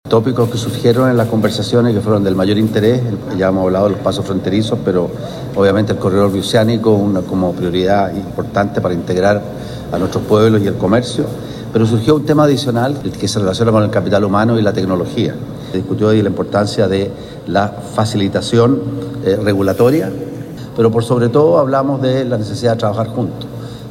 En esa línea, el canciller Francisco Pérez Mackenna explicó parte de los tópicos abordados en el encuentro, entre ellos, el corredor bioceánico y “la necesidad de trabajar juntos”.